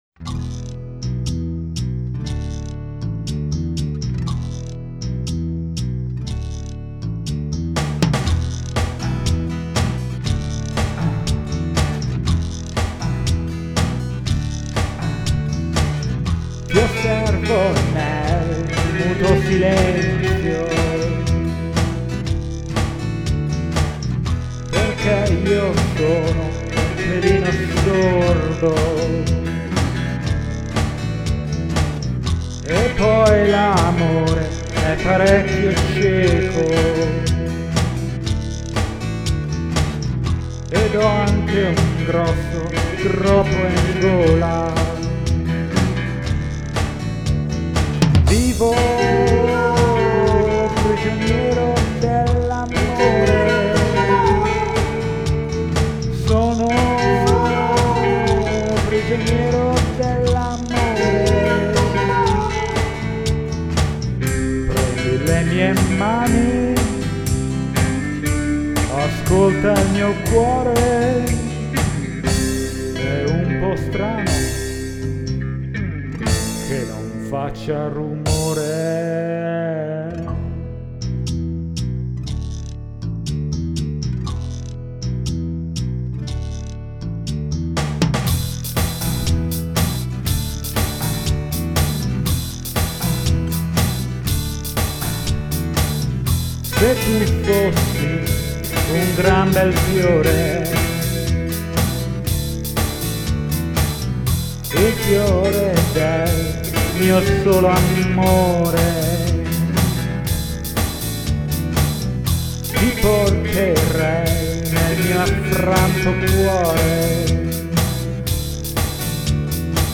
chitarrina acustica, chitarrina elettrificata e programming
vocette